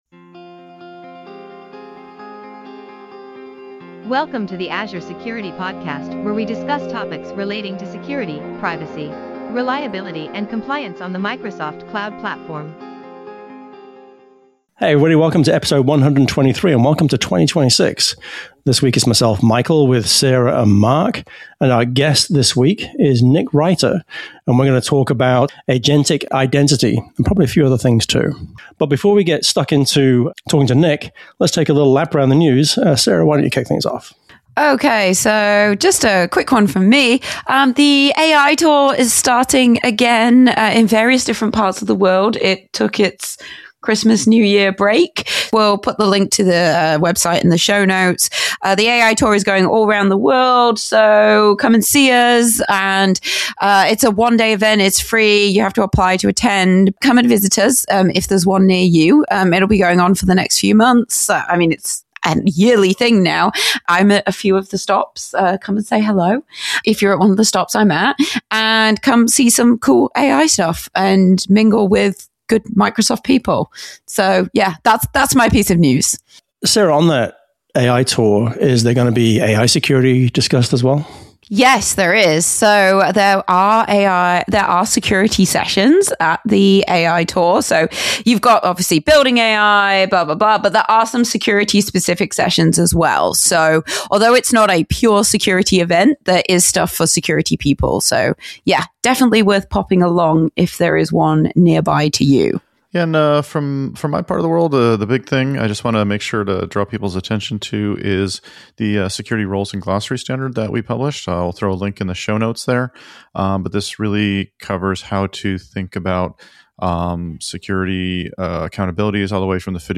This week on the GeekWire Podcast: We hit the road for a driving tour of the week’s news, making stops at Starbucks, Microsoft, and an Amazon Fresh store in its final days.